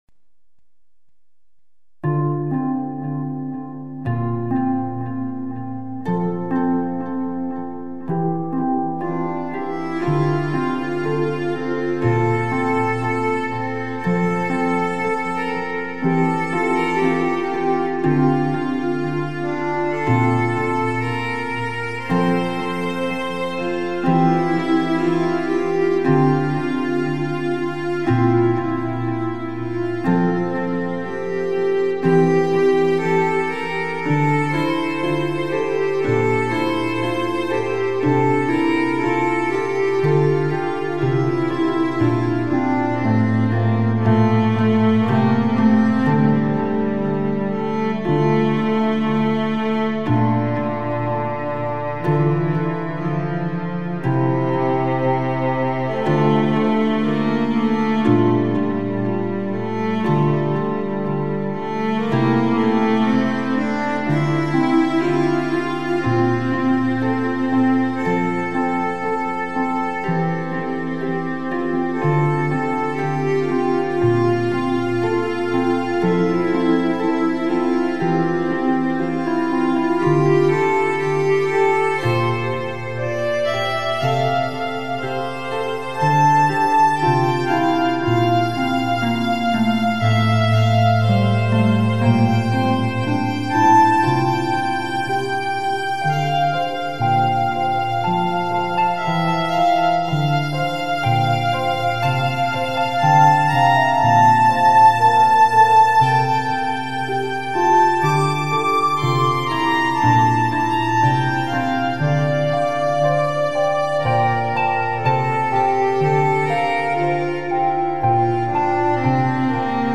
Recorded using a Roland AX-Synth into Zynewave Podium Free.